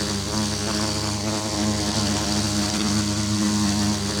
sounds / mob / bee / loop2.ogg